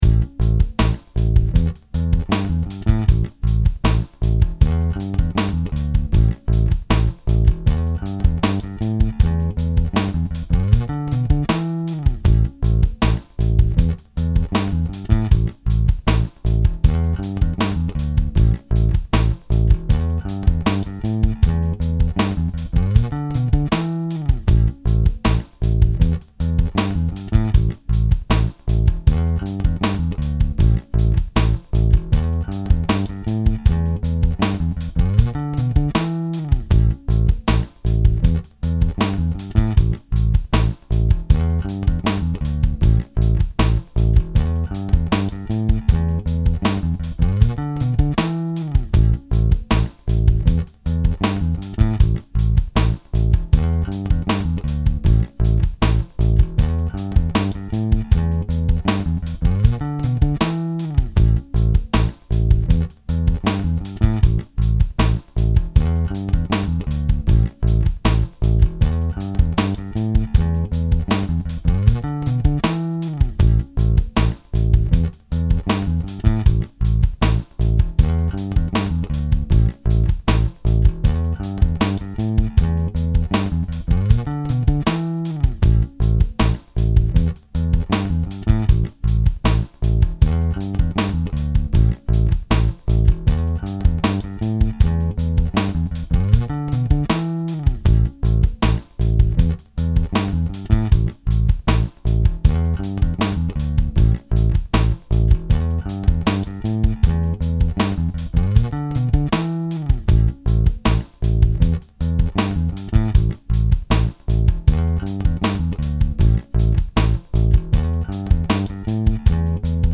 157 Rhythm Section